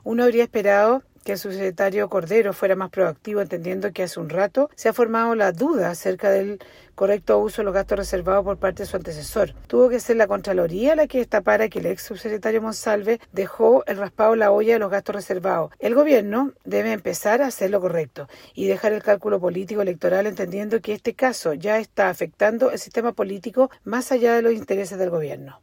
La diputada y jefa de la bancada de Renovación Nacional, Ximena Ossandón, criticó al subsecretario Cordero, emplazándolo a ser más “proactivo” ante el “raspado de olla” que habría dejado Monsalve.